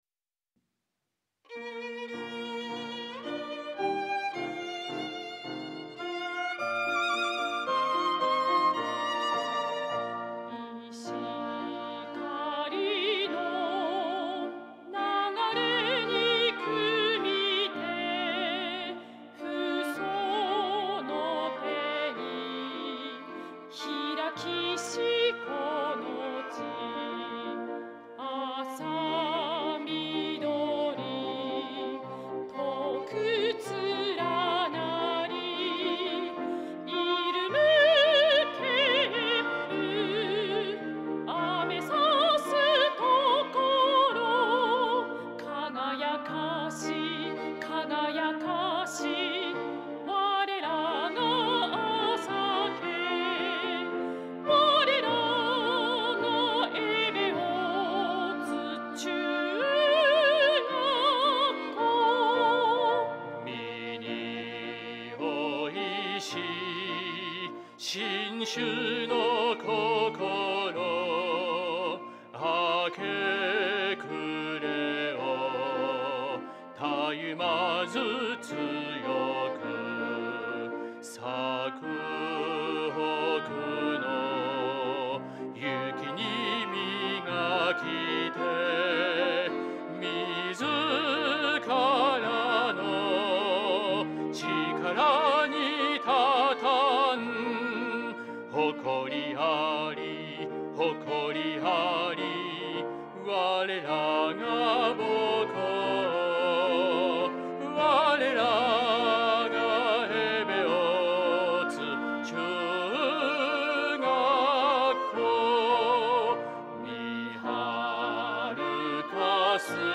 校歌
作詞：百田宗治／作曲：高木東六
演奏／アンサンブルグループ「奏楽（そら）」